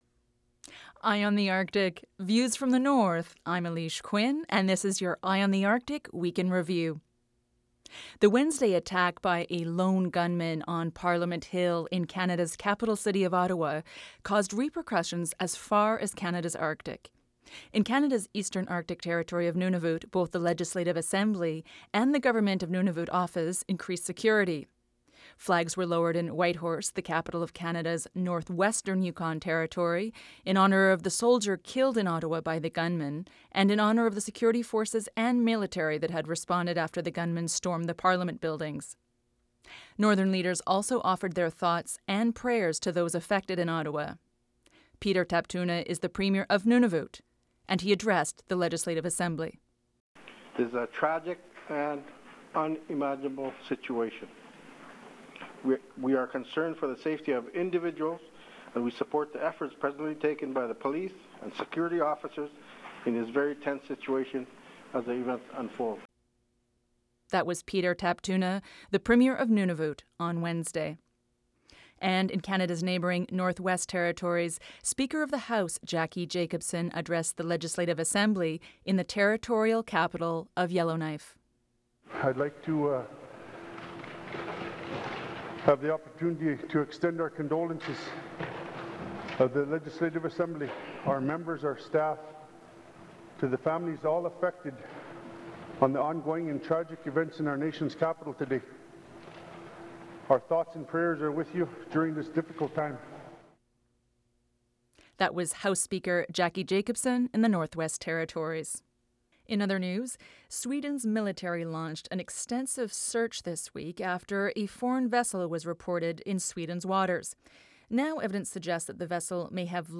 On this week’s news round-up, we bring you some of your most read stories on Eye on the Arctic this week: